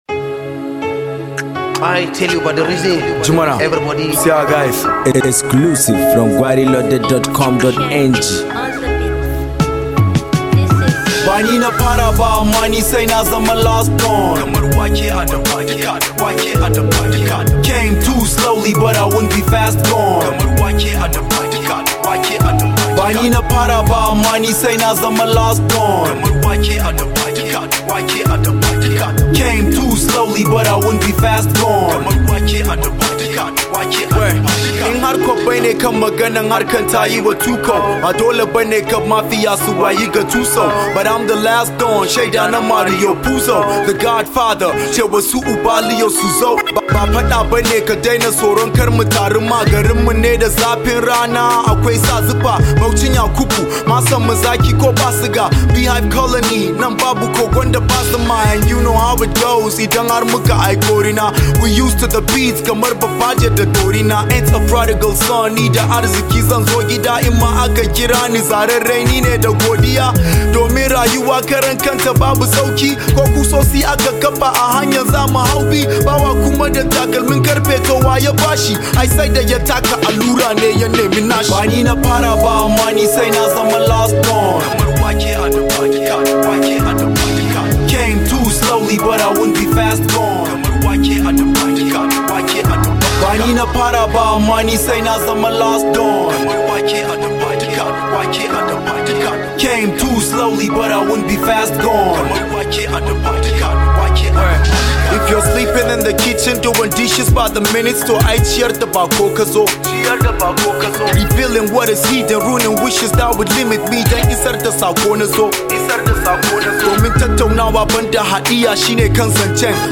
The Northern Hip-Hop Rapper